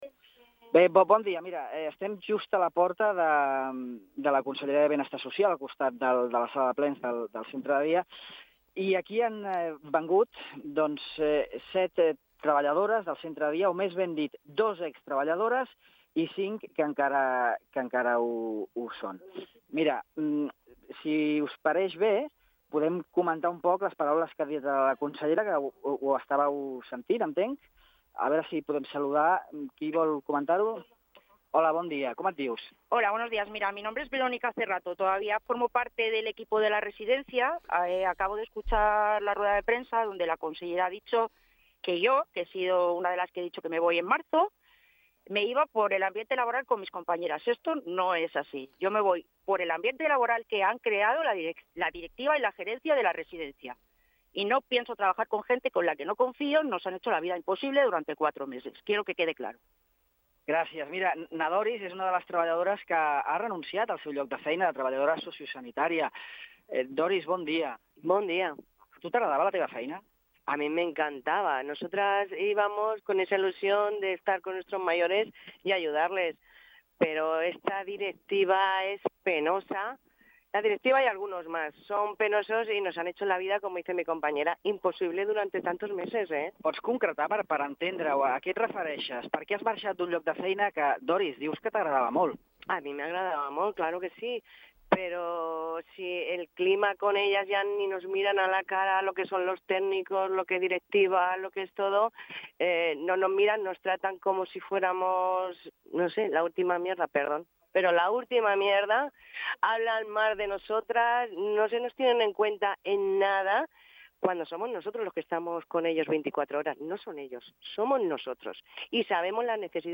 Ràdio illa ha donat veu aquest matí a la mitja dotzena de treballadores sociosanitàries que aquest matí han seguit de prop la compareixença de premsa de la consellera Cristina Costa amb relació a la delicada i controvertida situació que actualment es viu a la residència de majors de Formentera. Entre les treballadores, n’hi havia dues que ja han formalitzat la seva renúncia al seu lloc de feina al centre.